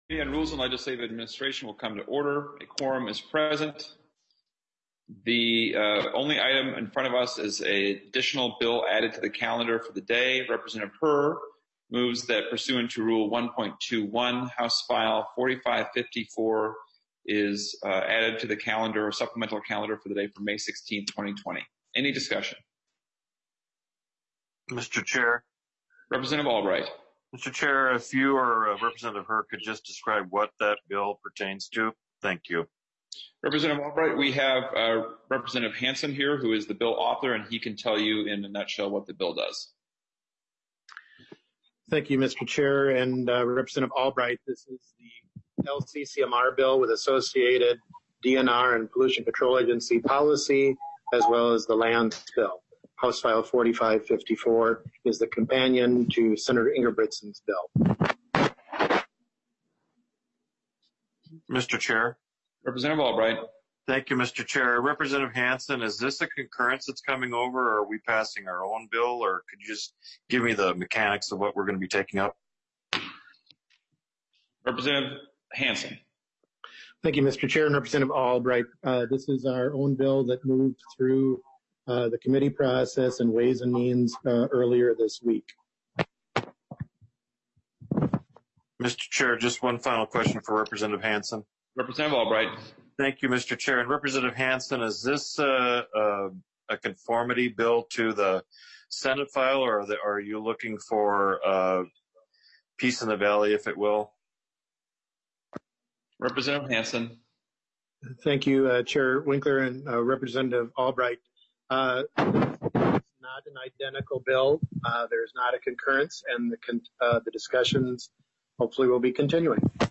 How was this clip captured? This remote hearing may be viewed live via the following methods: